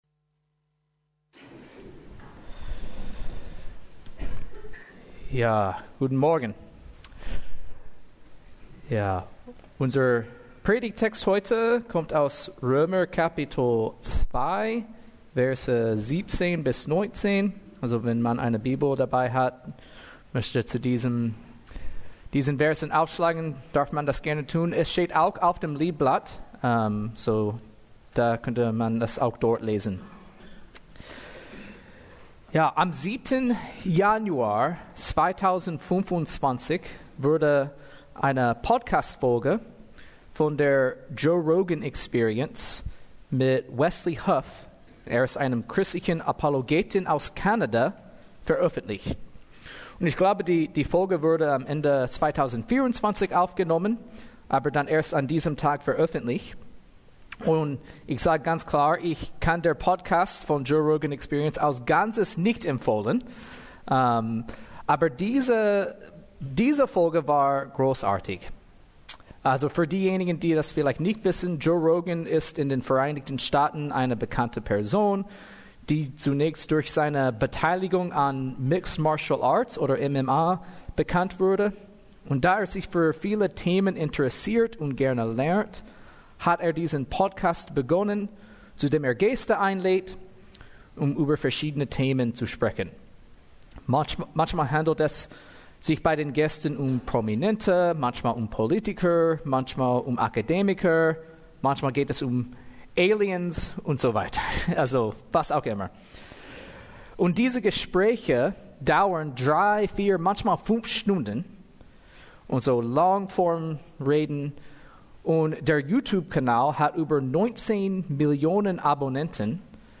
Predigt 23.02.2025 Römer 2, 17-29